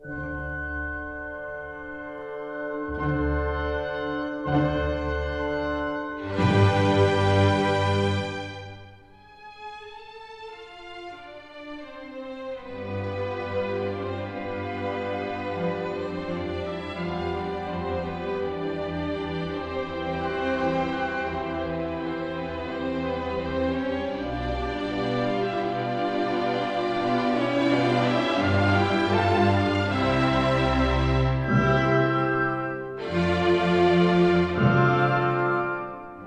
in C major, Op. 21
in the Kingsway Hall, London